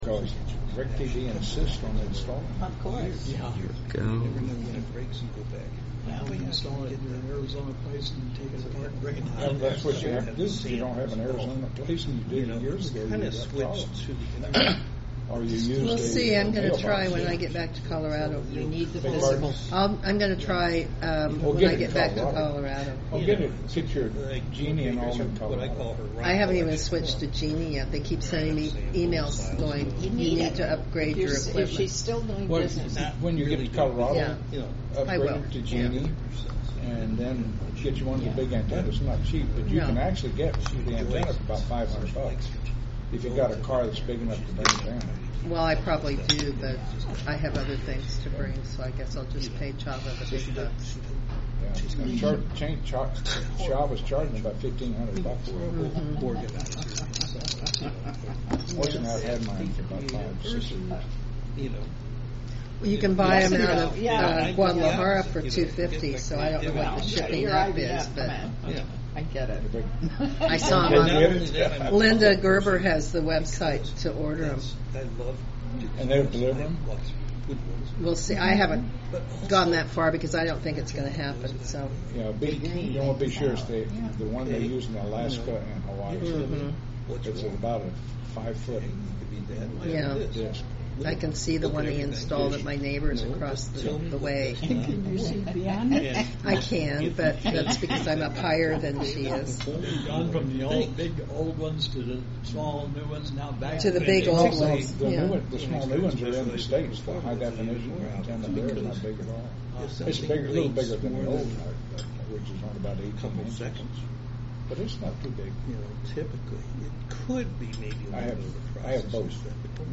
Researchers invent ‘anti-Wi-Fi’ paint that blocks wireless signals If you missed our meeting in real-time, then you can always listen to this podcast.